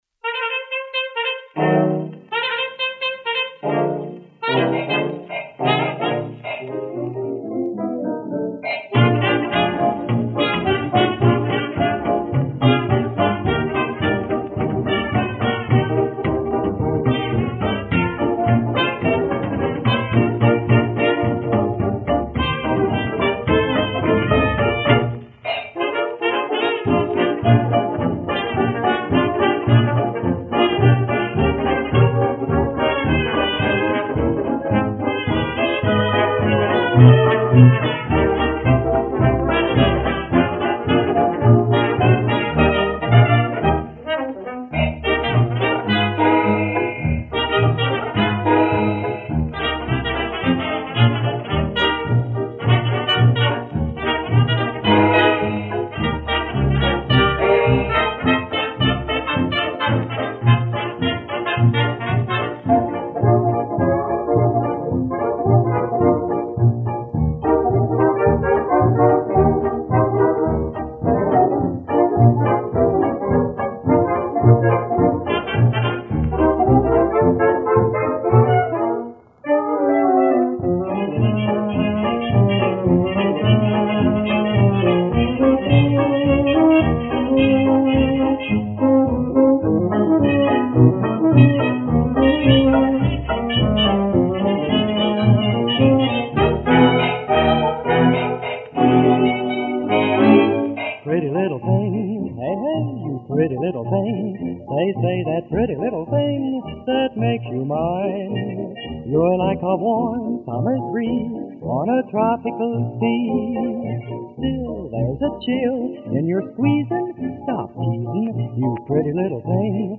in a 1927 recording made in the Multnomah Hotel.